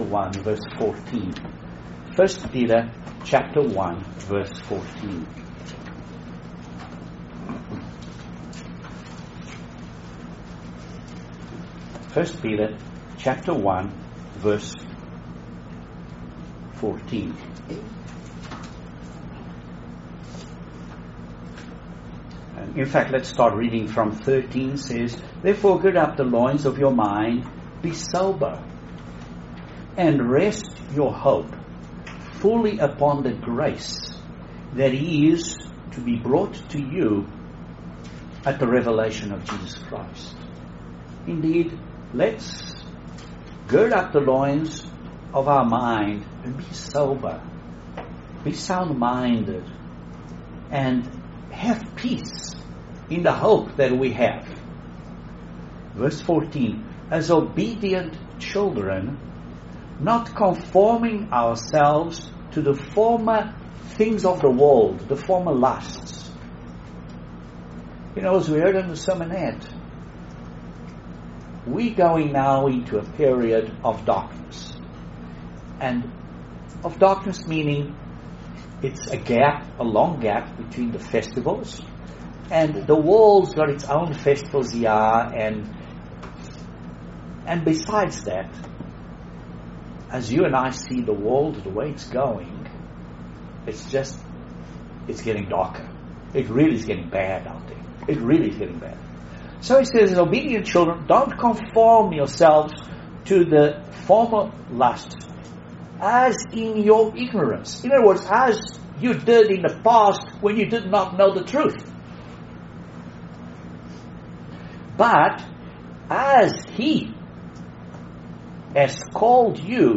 Excellent Sermon on how to be Holy as God commands us to be.